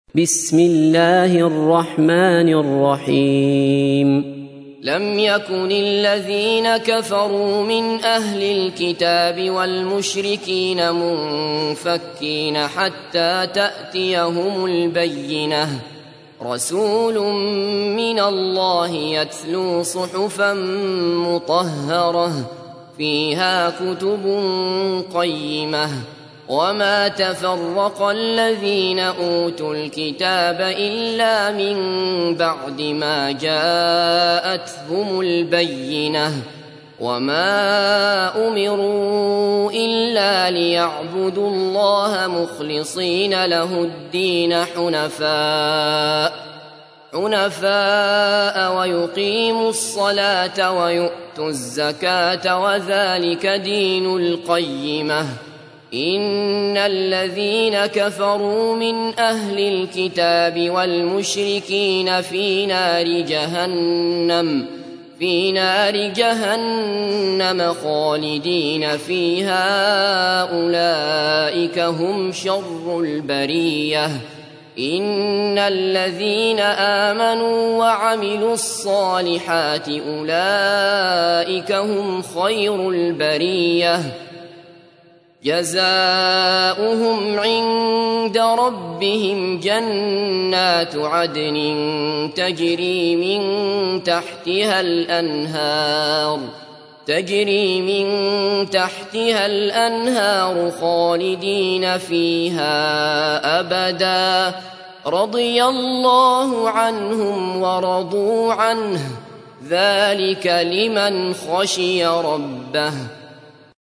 تحميل : 98. سورة البينة / القارئ عبد الله بصفر / القرآن الكريم / موقع يا حسين